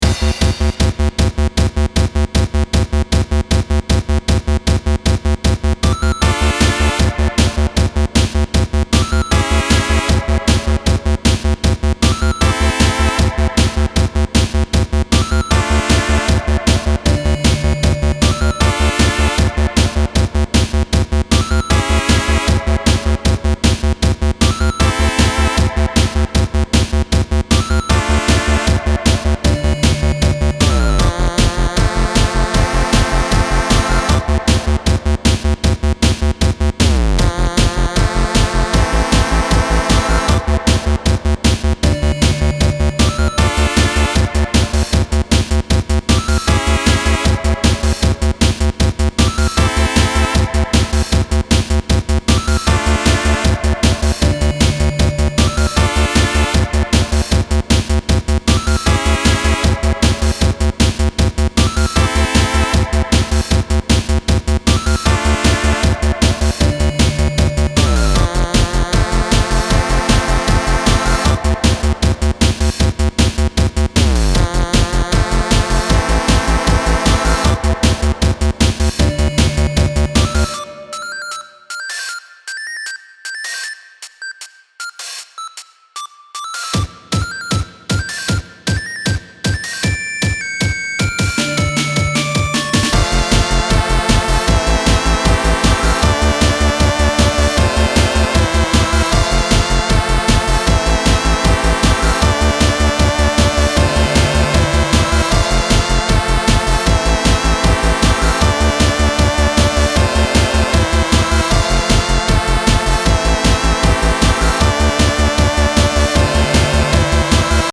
Style Style Chiptune
Mood Mood Driving, Uplifting
Featured Featured Synth
BPM BPM 155